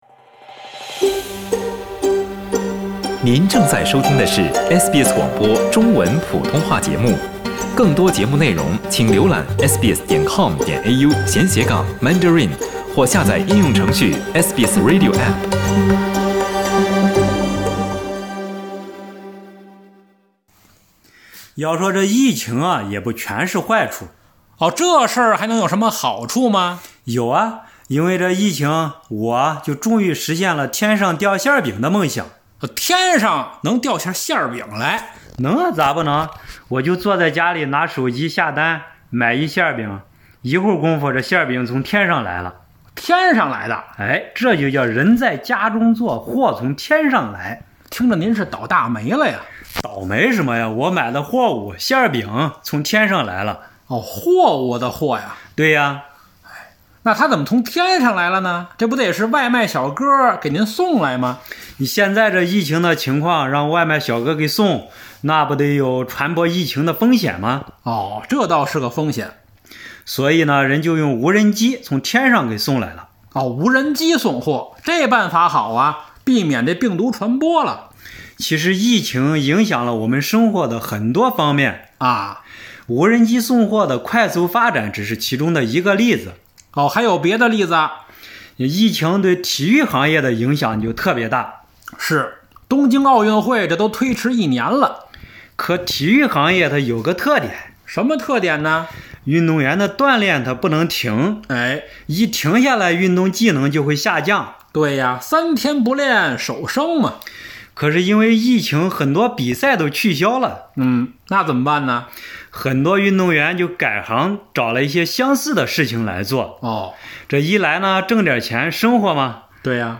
相聲作品：改行